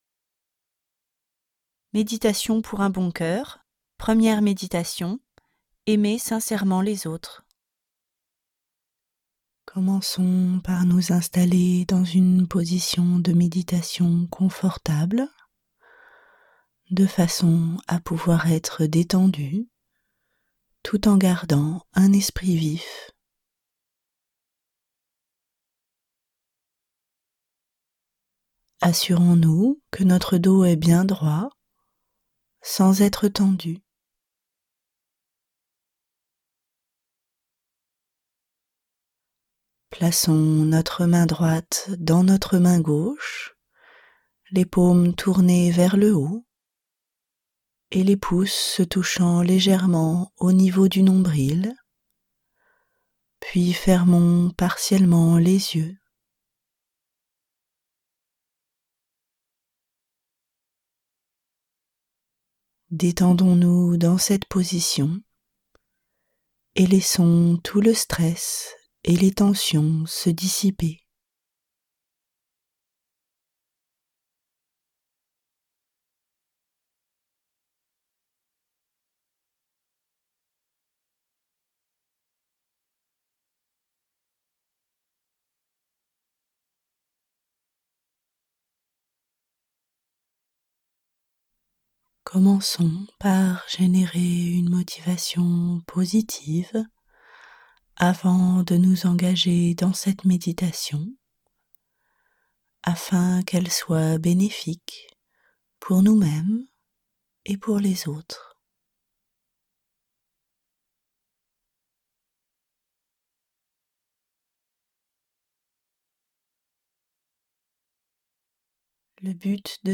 Lire un extrait Guéshé Kelsang Gyatso Méditations pour un bon cœur Le pouvoir guérisseur de l'amour Editions Tharpa Date de publication : 2016 Ces trois méditations guidées sont simples et peuvent être pratiquées par tout le monde. Grâce à une pratique régulière, ces méditations nous aident à développer la paix de notre esprit et notre bonheur, et à améliorer nos relations avec les autres en apprenant à prendre soin d'eux. 7 , 00 € Prix format CD : 12,00 € Ce livre est accessible aux handicaps Voir les informations d'accessibilité